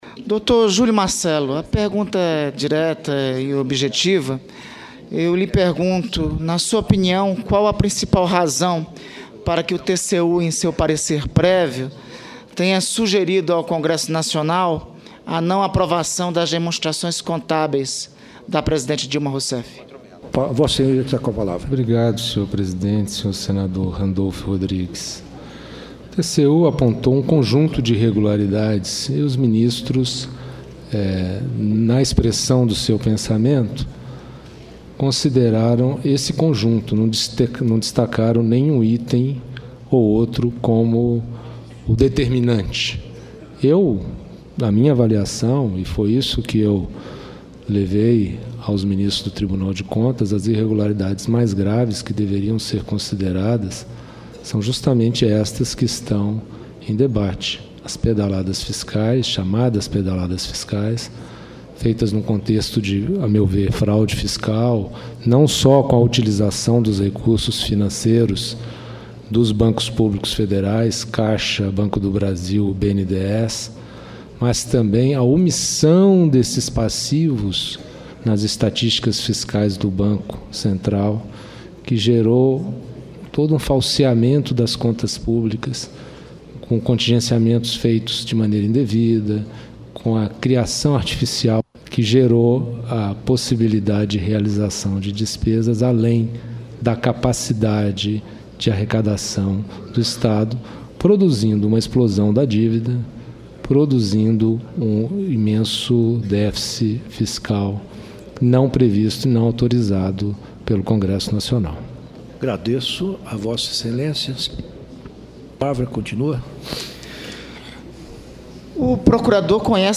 Oitiva do informante procurador Júlio Marcelo de Oliveira
Julgamento do Impeachment